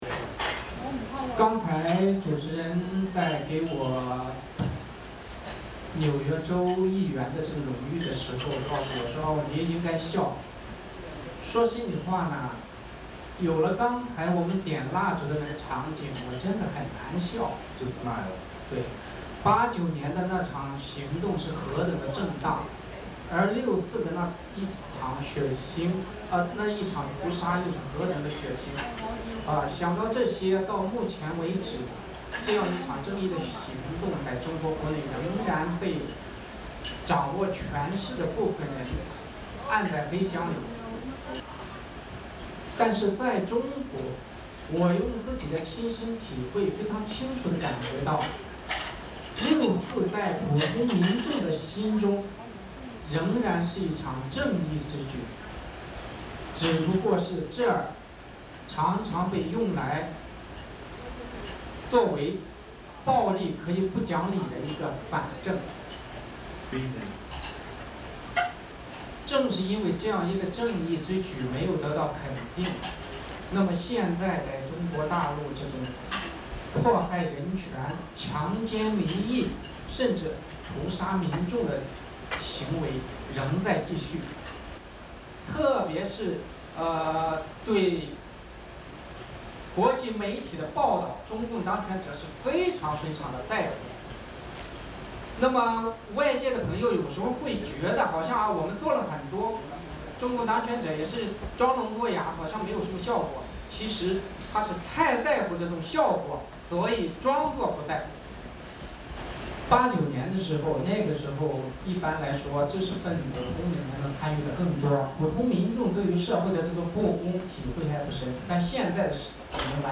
著名盲人维权律师陈光诚星期六（1日）在纽约举行的一次“六四”纪念活动上表示，这场正义的行动的真相在中国国内仍被关在黑箱中，也正因为此，中国大陆人权、强奸民意的行为仍在继续。